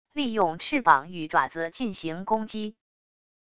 利用翅膀与爪子进行攻击wav音频